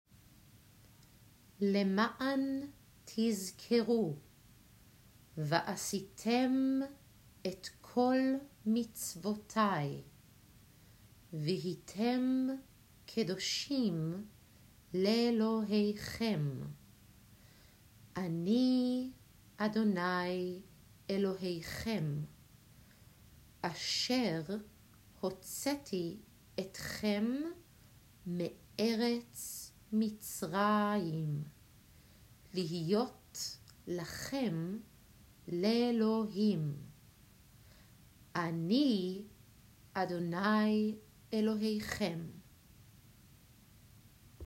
L’ma’an (Read)
Lmaan-read.m4a